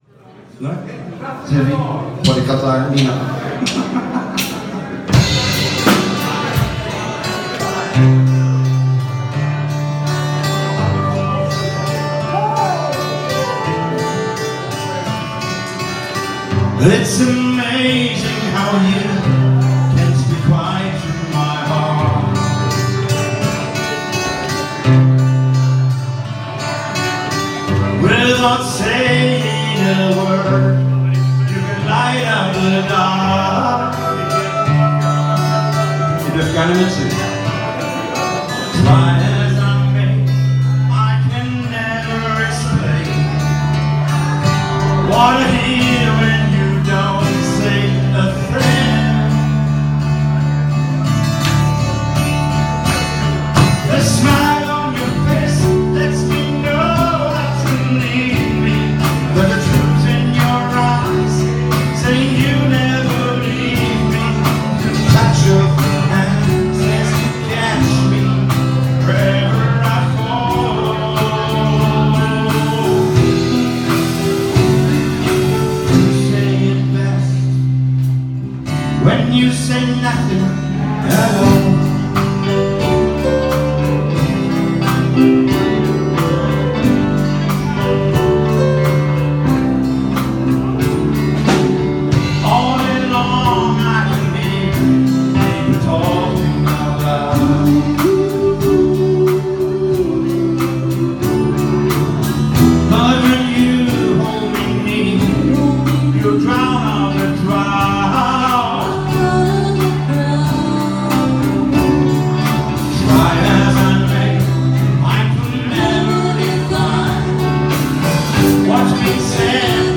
Mischung aus Rock, Pop, Blues, Funk und Soul
lead vocals, backing vocals
guitar, sax, backing vocals
keyboard
drums, backing vocals